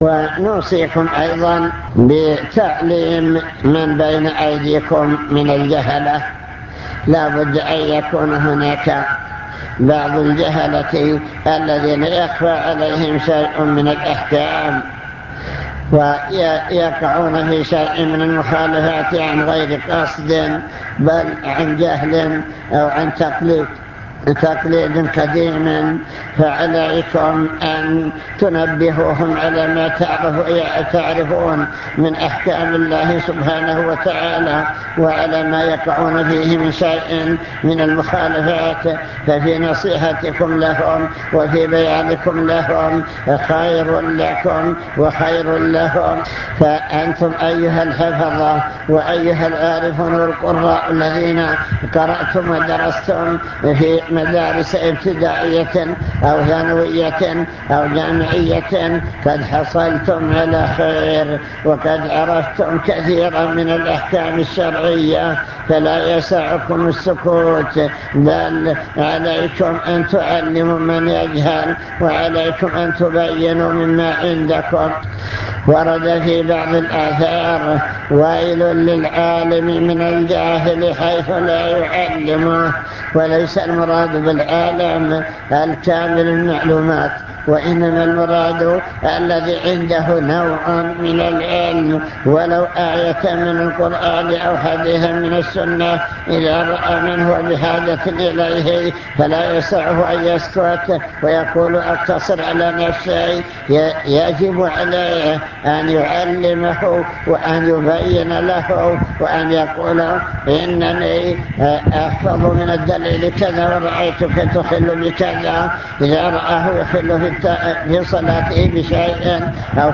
المكتبة الصوتية  تسجيلات - محاضرات ودروس  محاضرة بعنوان من يرد الله به خيرا يفقهه في الدين